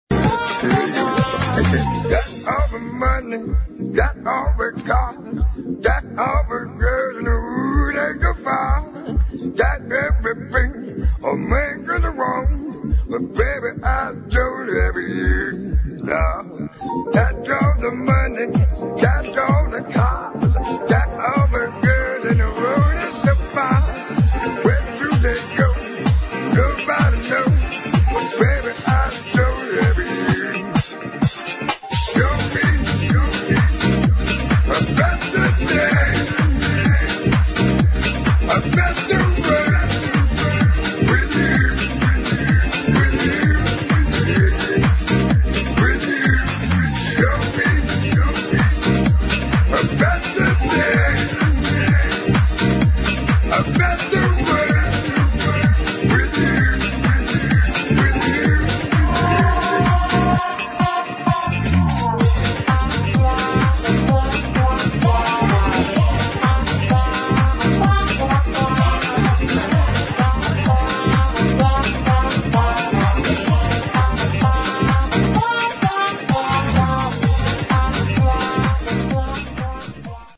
[ HOUSE / DISCO ]